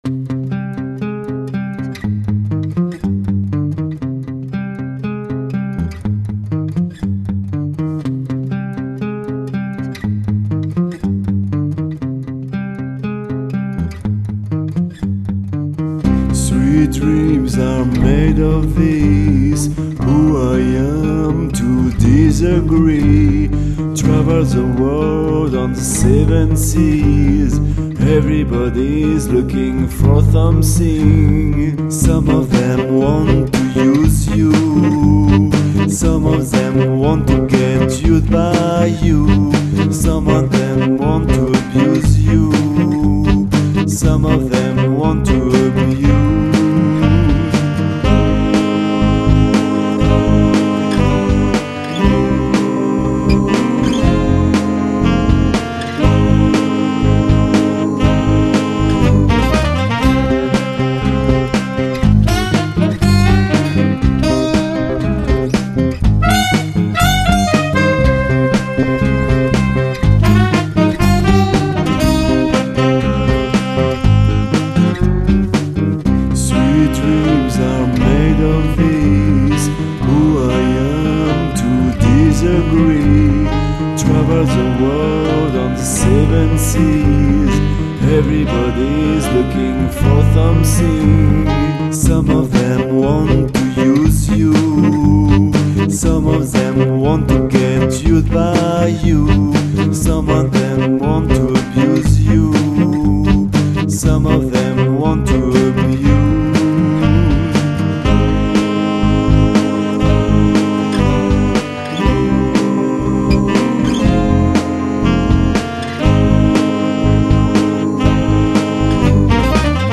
version + Sax